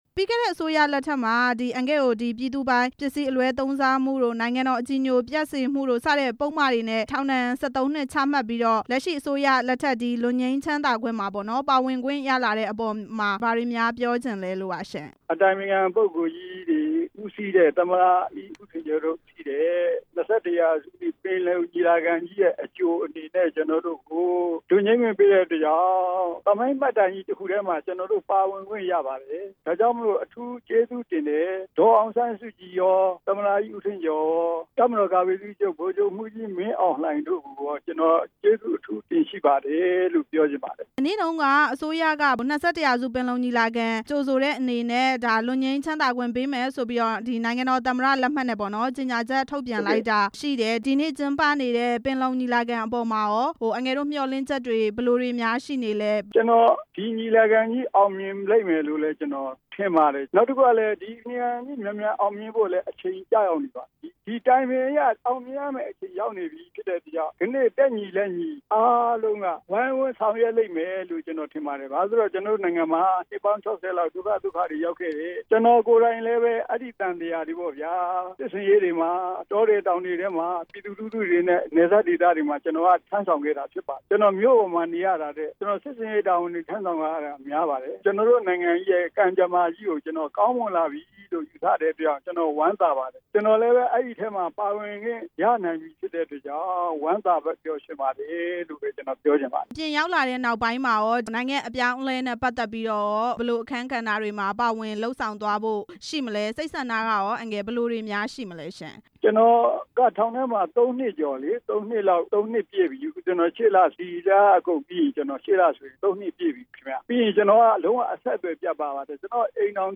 လွတ်မြောက်လာတဲ့ သာသနာရေး ဝန်ကြီးဟောင်း ဦးဆန်းဆင့်နဲ့ မေးမြန်းချက်